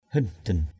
/hɪn-d̪ɪn/ (t.) khốn nạn, khốn khổ = misérable. manuis hindin mn&{X h{Nd{N kẻ khốn nạn, người khốn khổ.
hindin.mp3